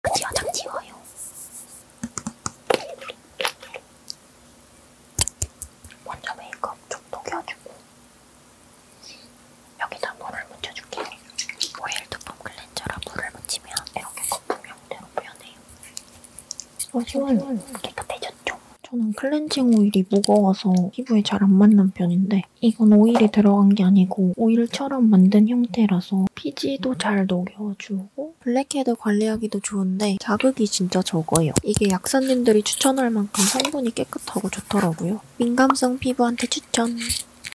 같이 세수하는 asmr🧴🫧 원래 클렌징오일이 sound effects free download